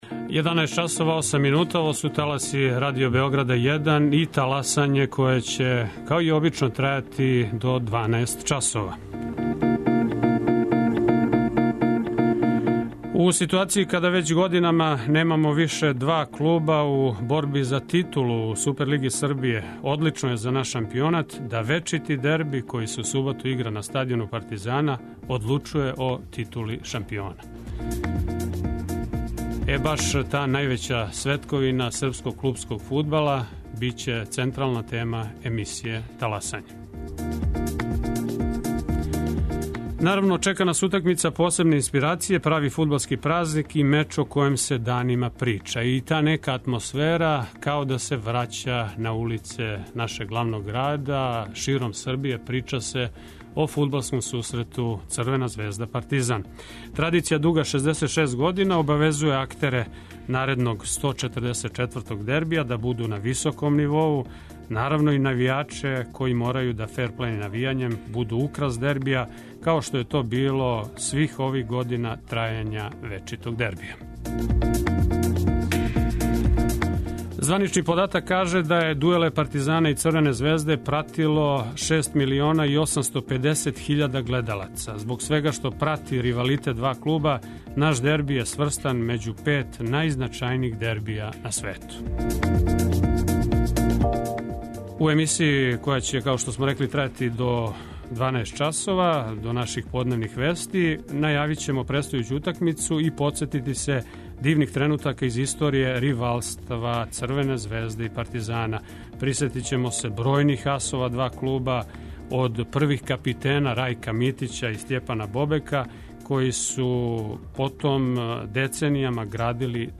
Гости Таласања су бивши фудбалери Партизана и Црвене звезде, сада тренери